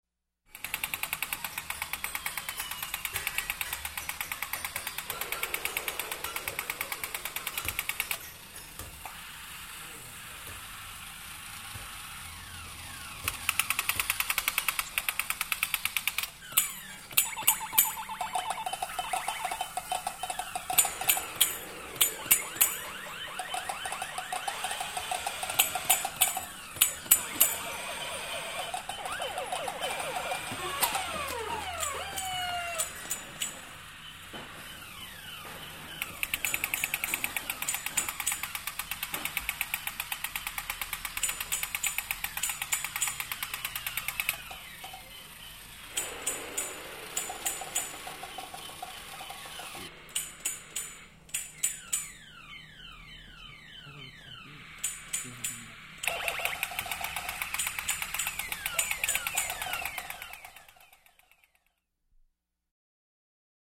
На этой странице собраны звуки магазина игрушек: весёлая суета, голоса детей, звон кассы, фоновые мелодии.
Звуки детских игрушек в магазине